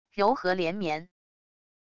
柔和连绵wav音频